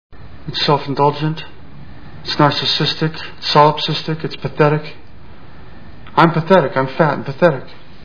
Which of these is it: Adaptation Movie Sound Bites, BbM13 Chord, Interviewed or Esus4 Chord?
Adaptation Movie Sound Bites